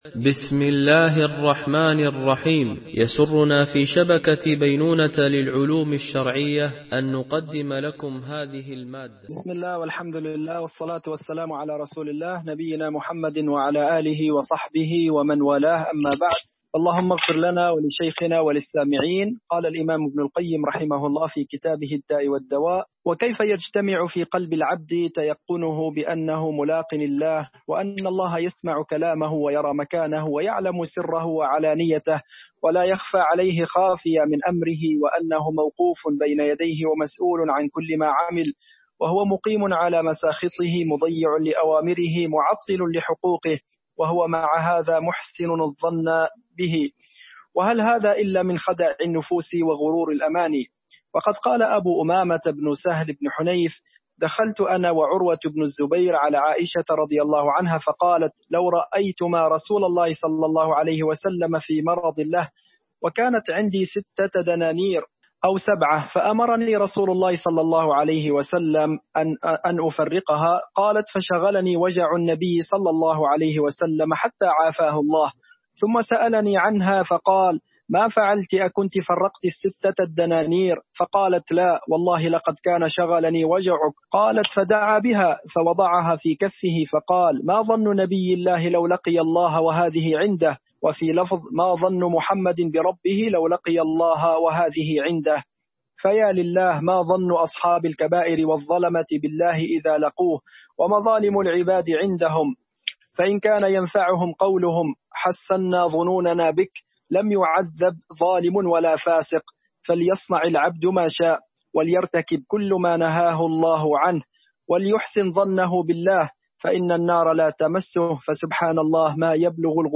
شرح كتاب الداء والدواء ـ الدرس 7